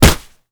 kick_hard_impact_02.wav